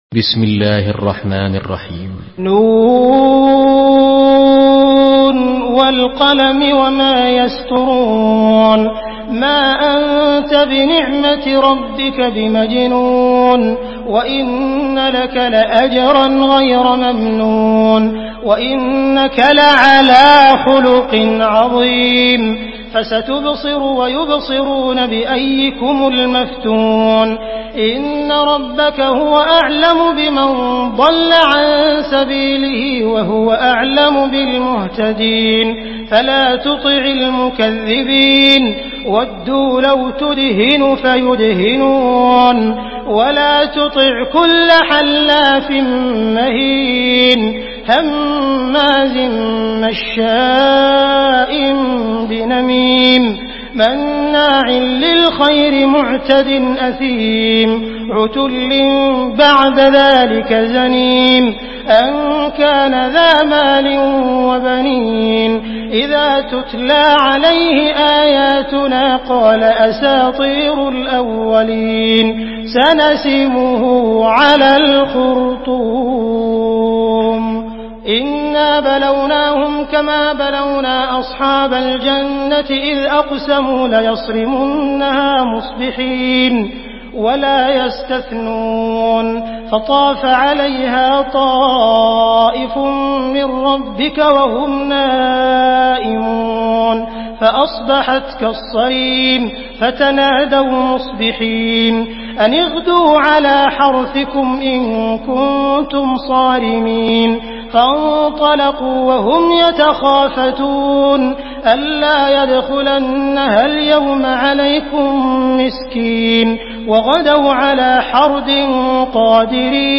Surah القلم MP3 by عبد الرحمن السديس in حفص عن عاصم narration.
مرتل حفص عن عاصم